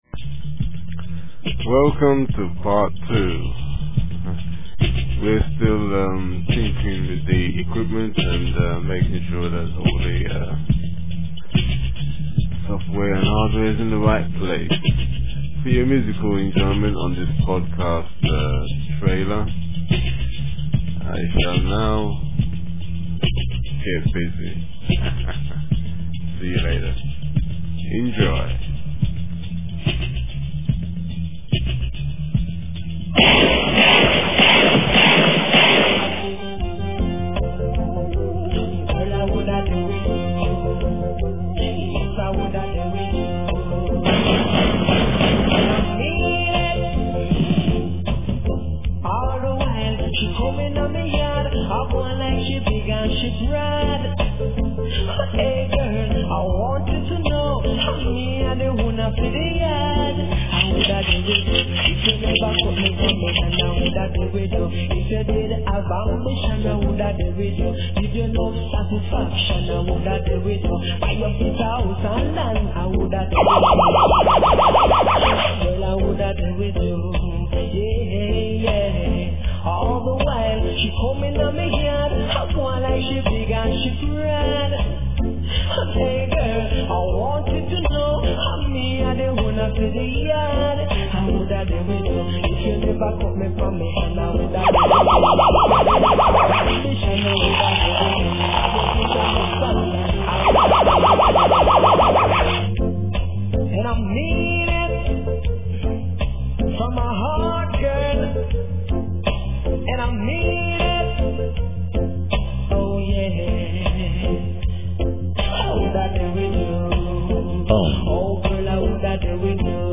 reggae music. This is a trailer.